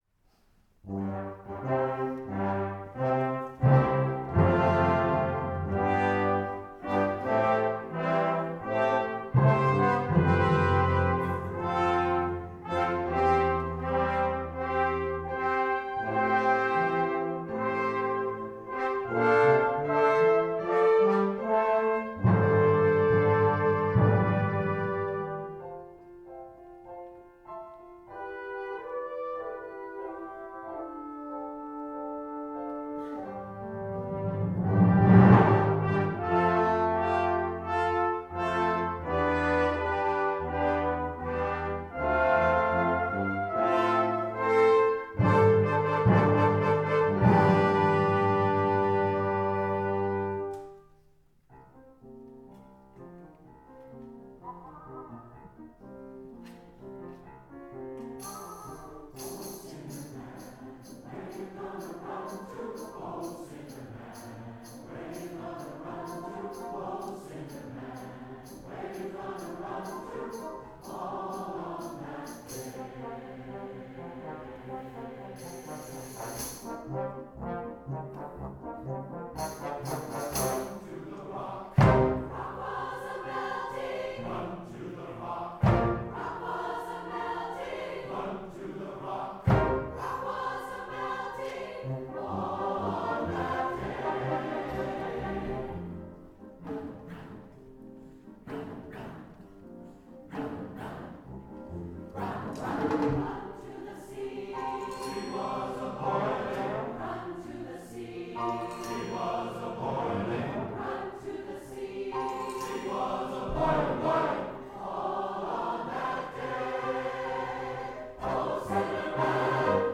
for SATB Chorus, Brass Quintet, Percussion, and Piano (2005)
Audience participation is featured.
This is energetic music.